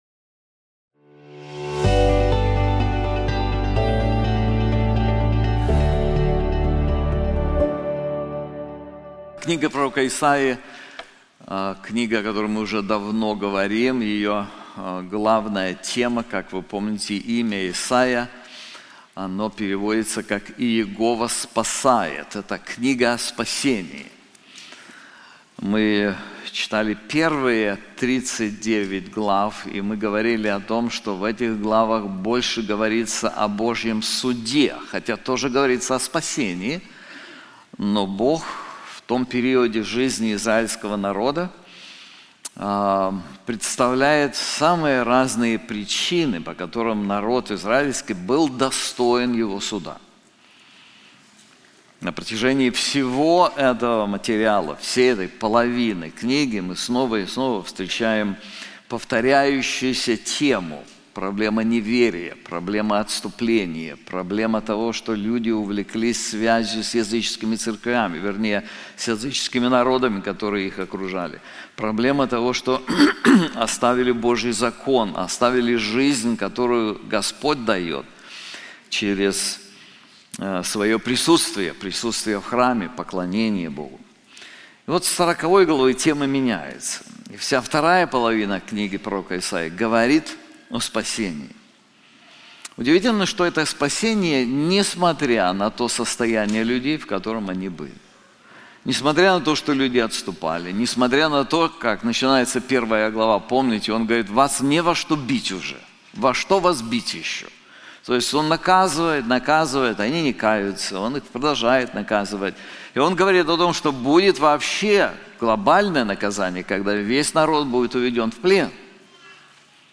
This sermon is also available in English:Salvation to the Ends of the Earth • Isaiah 45:22-24